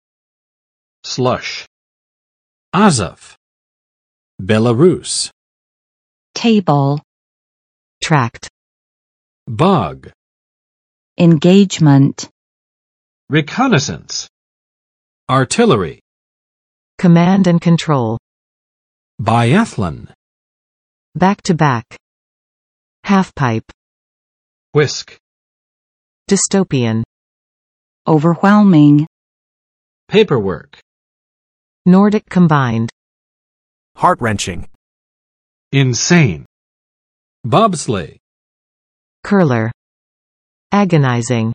Vocabulary Test - February 14, 2022
[slʌʃ] n. 融雪；泥泞
slush.mp3